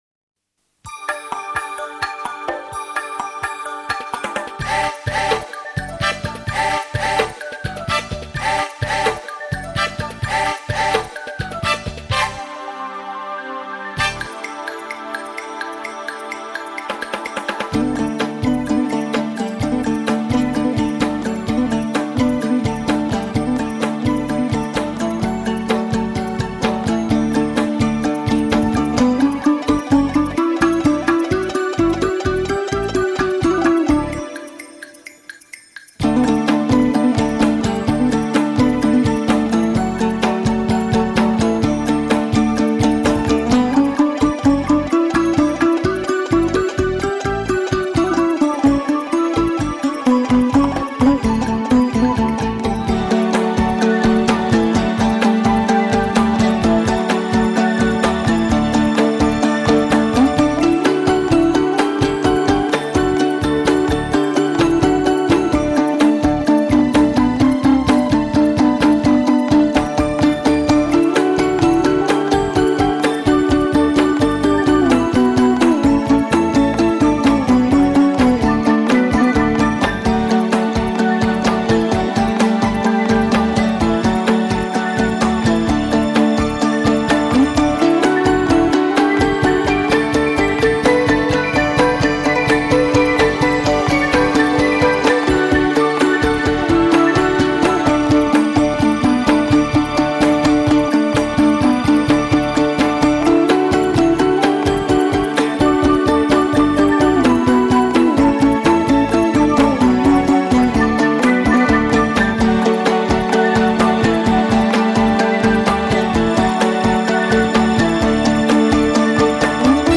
это яркий и эмоциональный куи